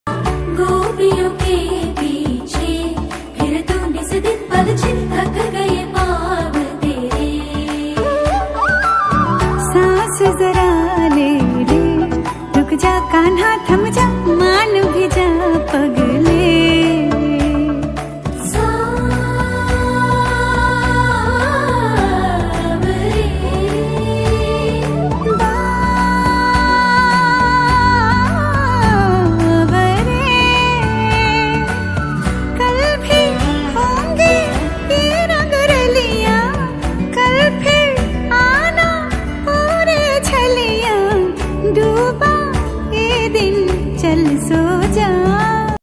6TypeRomantic / Love Song Ringtone